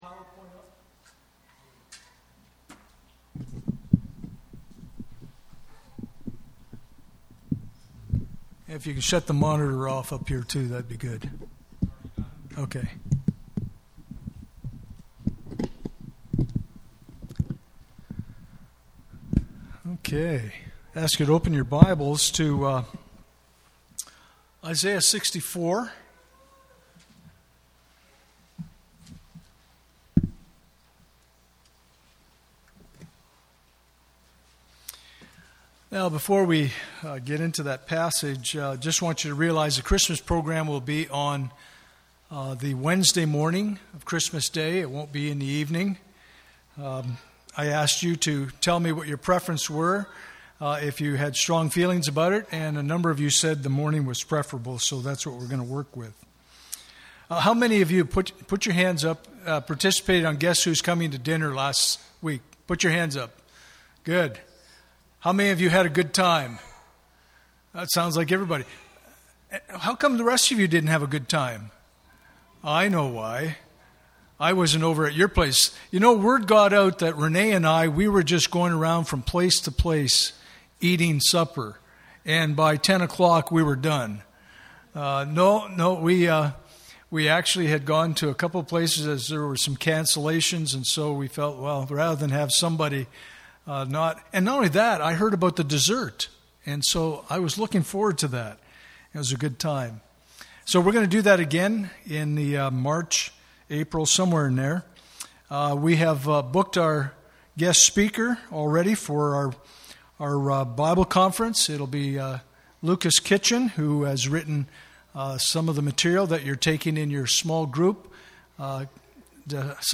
Passage: Isaiah 55:1-11 Service Type: Sunday Morning « Good News